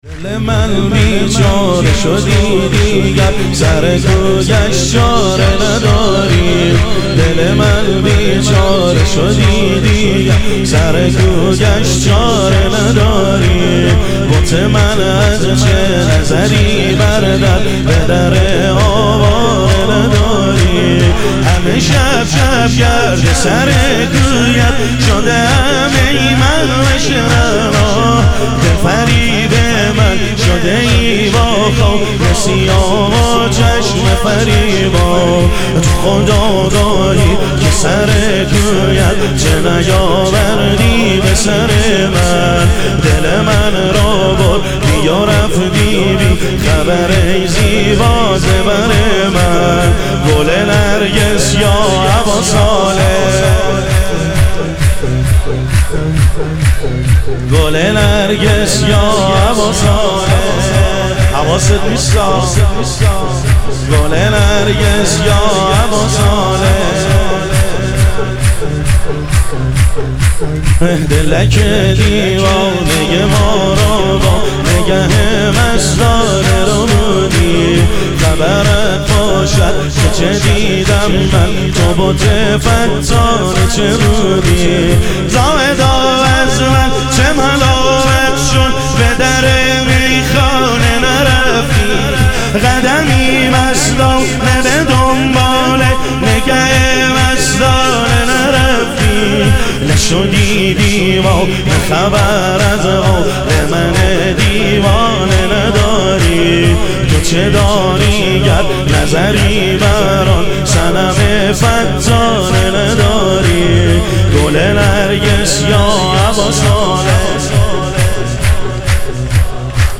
شور
شب ظهور وجود مقدس حضرت مهدی علیه السلام